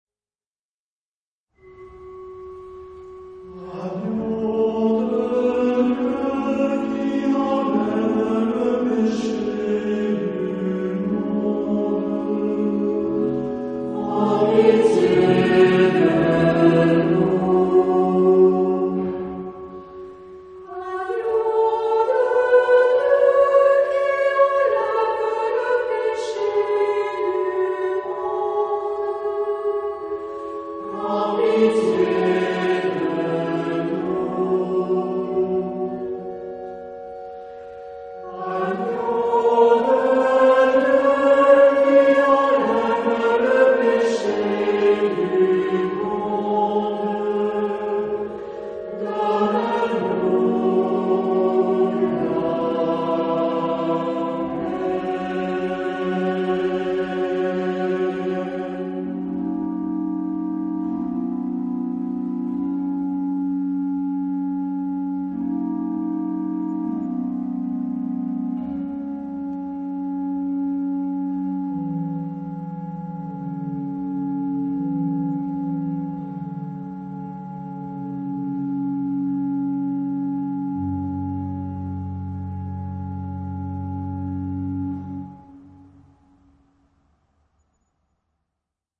Genre-Style-Form: Mass
Mood of the piece: imploring
Type of Choir:  (1 unison voices )
Instruments: Organ (1)
Tonality: G minor